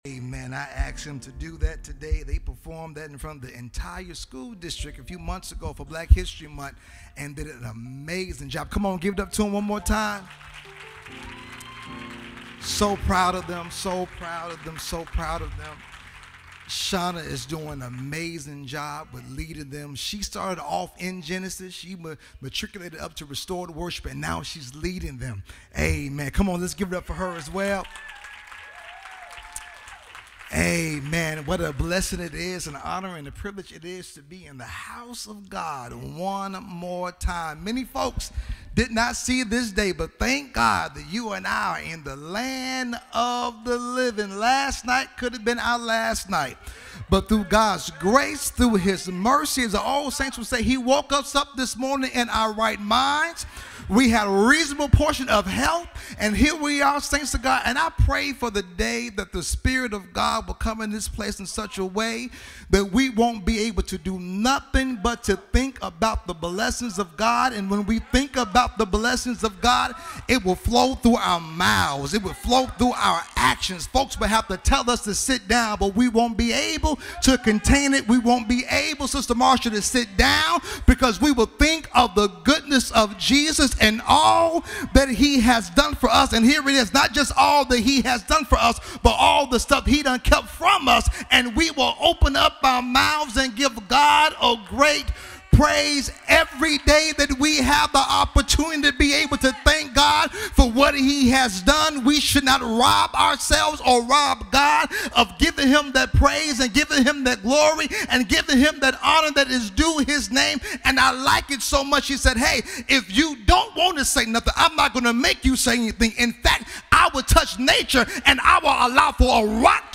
Hopewell Missionary Baptist Church, Carbondale IL
audio sermon